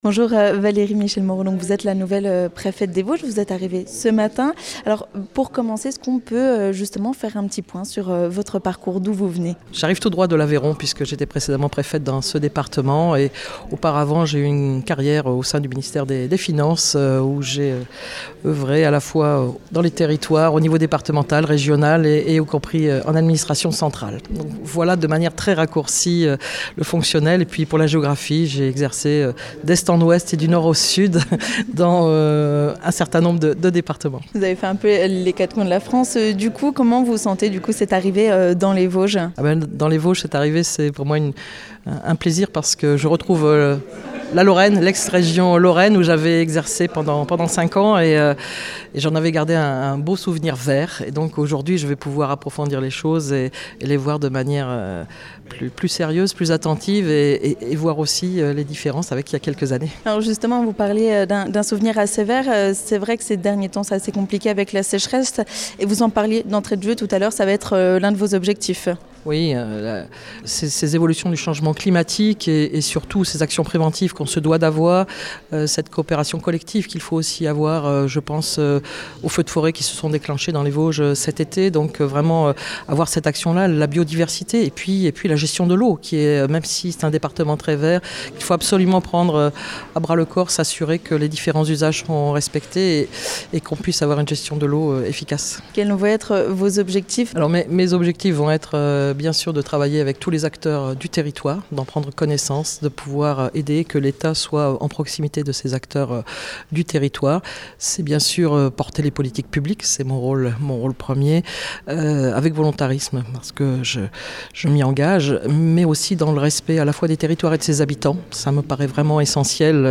Elle a pris ses fonctions hier, lundi ! A l'occasion d'une rencontre avec les médias locaux, nous sommes allés lui poser quelques questions.
Ecoutez la nouvelle préfète des Vosges, Valérie Michel-Moreaux sur Vosges FM !